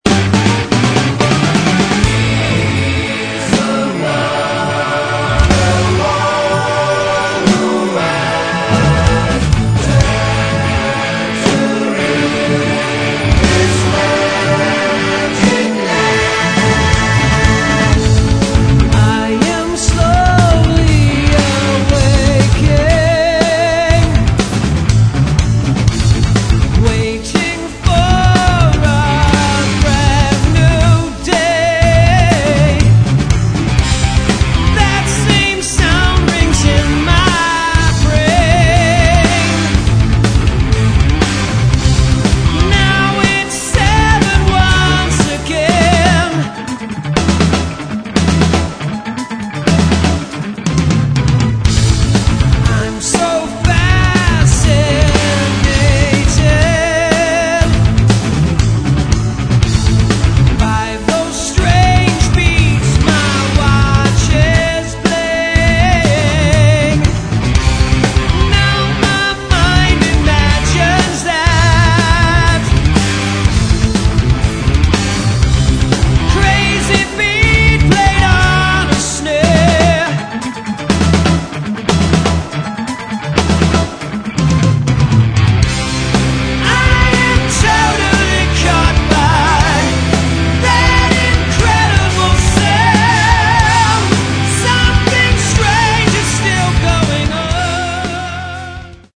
Drums & Percussions
(low quality)